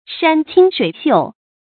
山清水秀 注音： ㄕㄢ ㄑㄧㄥ ㄕㄨㄟˇ ㄒㄧㄨˋ 讀音讀法： 意思解釋： 清：純凈；秀：秀麗。形容風景優美。